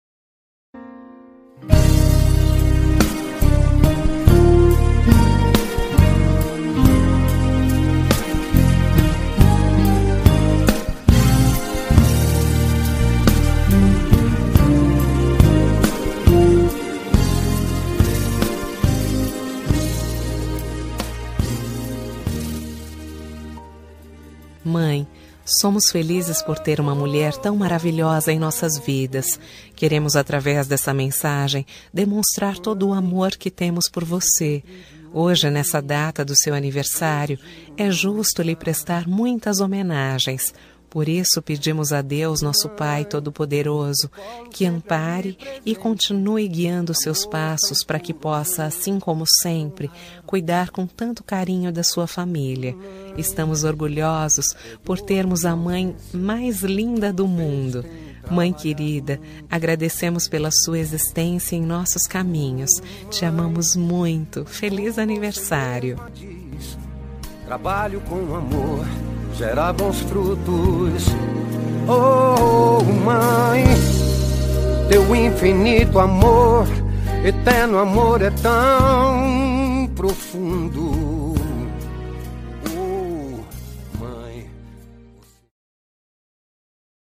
Aniversário de Mãe – Voz Feminina – Cód: 035371